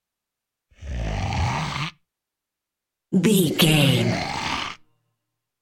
Monster growl snarl small creature x2
Sound Effects
scary
ominous
angry